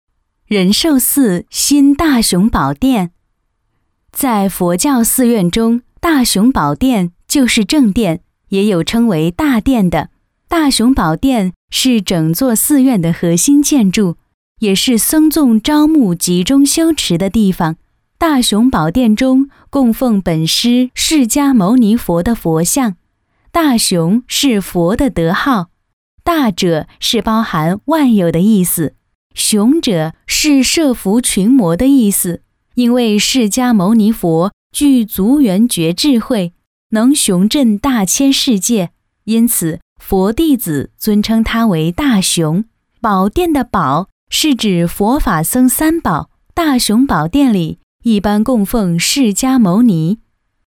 自然诉说 企业专题,人物专题,医疗专题,学校专题,产品解说,警示教育,规划总结配音
时尚甜美女音，亲切温柔，自然清新。